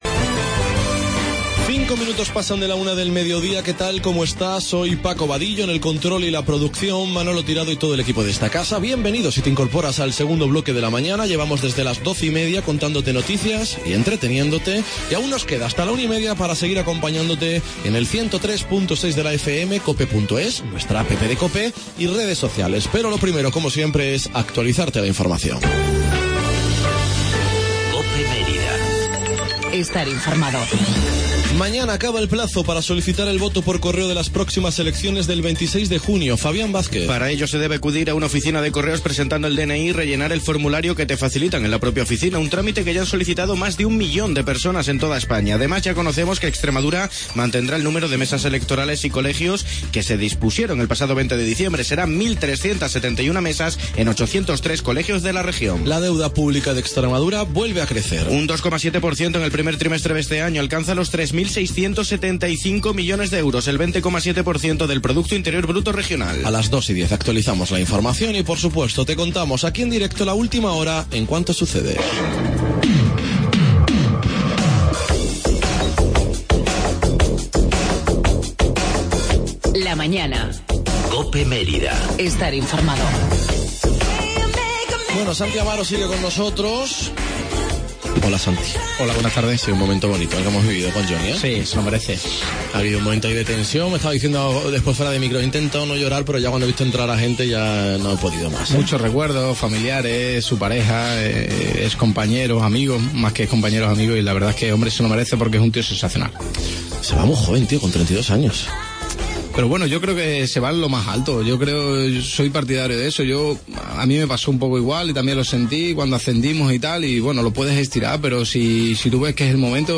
TERTULIA EUROCOPE 15-06-16 COPE MÉRIDA